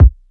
KICK_DEATH_DO_US_PART.wav